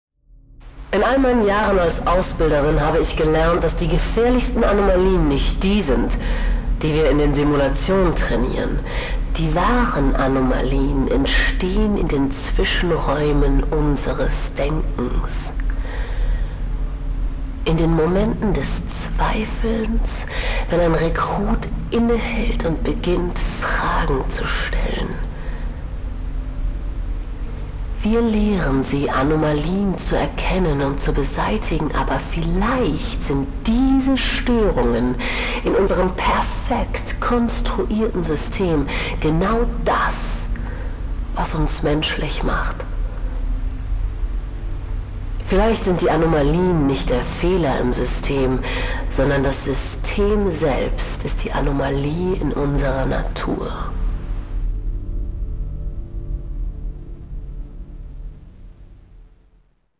Begleitet von binauralem Sounddesign erleben Sie ein akustisches Abenteuer, das Sie mitten in die Inszenierung zieht.